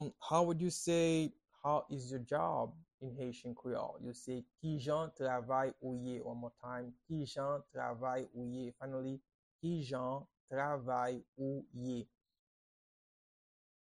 Pronunciation and Transcript:
How-is-your-job-in-Haitian-Creole-–-Kijan-travay-ou-ye-pronunciation-by-a-Haitian-teacher.mp3